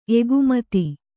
♪ egumati